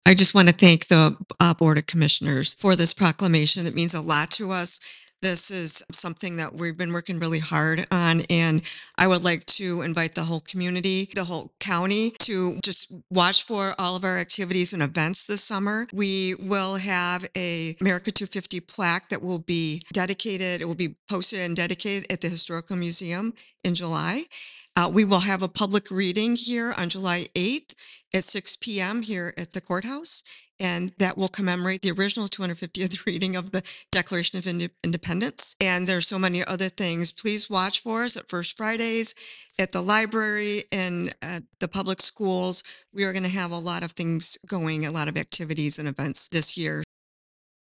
A representative from the chapter thanked the board and outlined plans for a series of events marking the nation’s 250th anniversary.